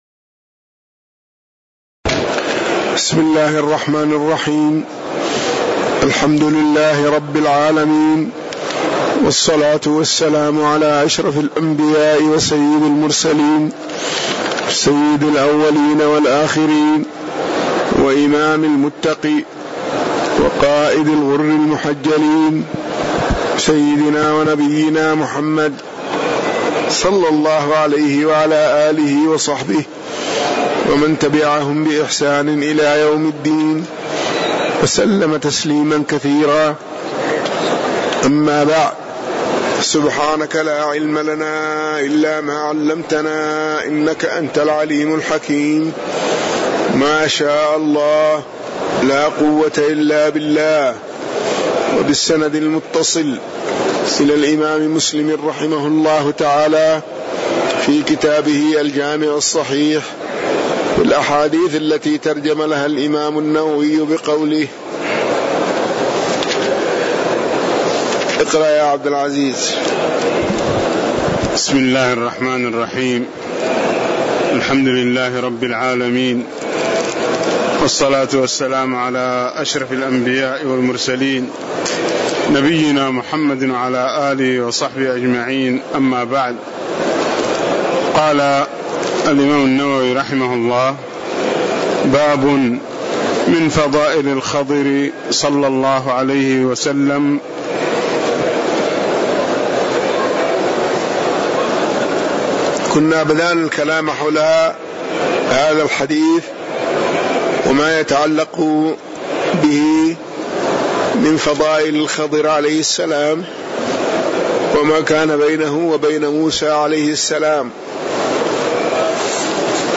المكان: المسجد النبوي